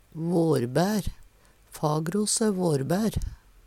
vårbær - Numedalsmål (en-US)